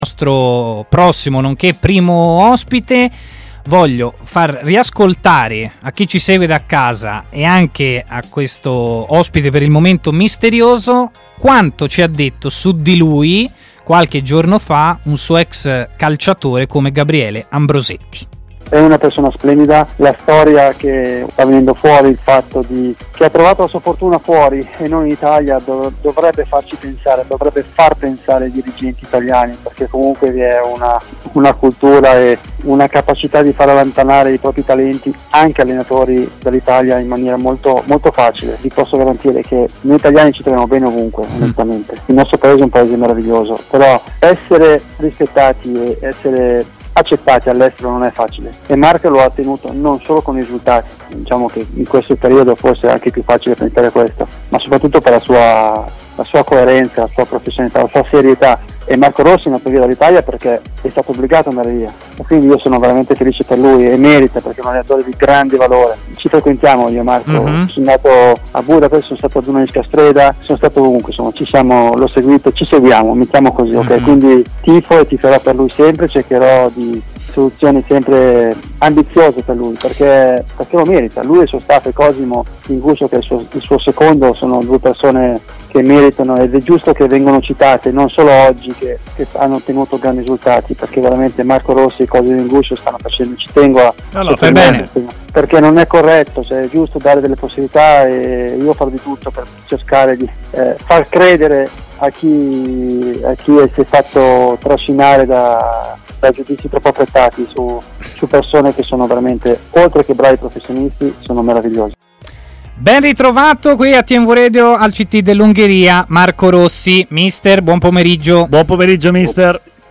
ha parlato in diretta a Stadio Aperto, trasmissione di TMW Radio